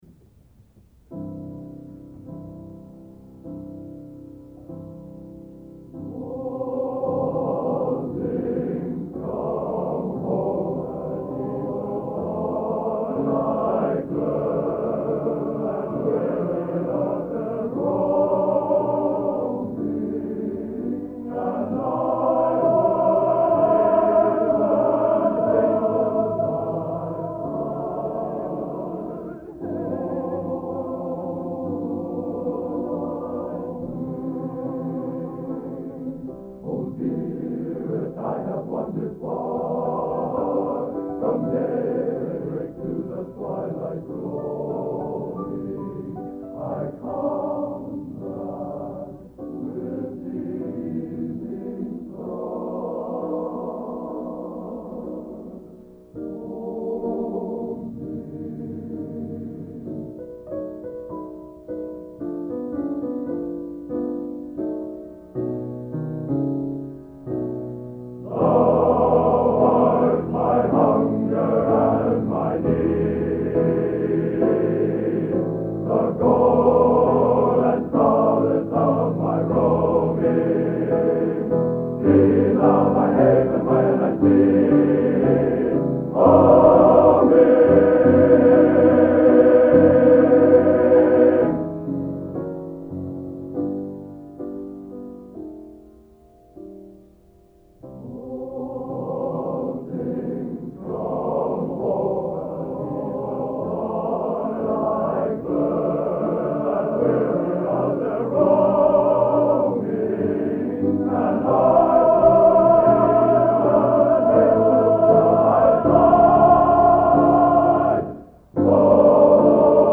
Collection: End of Season, 1971
Location: West Lafayette, Indiana